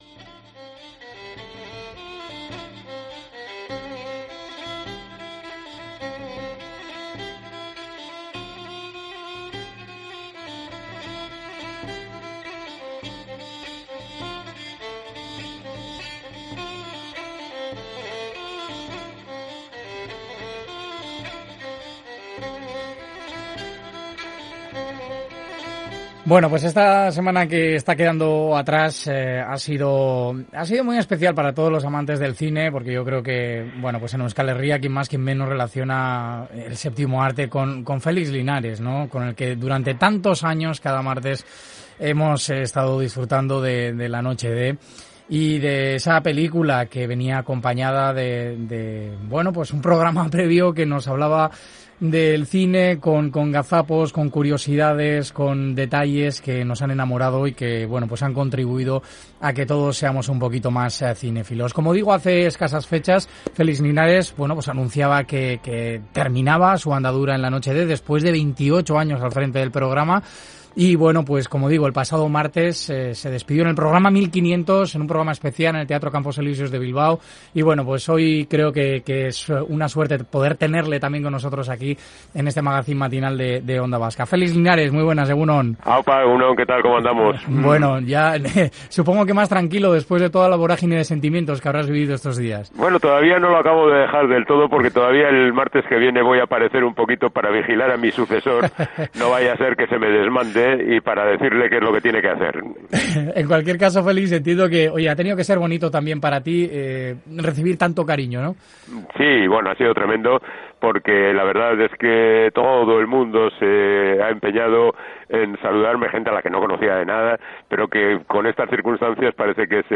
la que más veces ha visto en su vida... una charla en la que el tiempo se te pasará volando.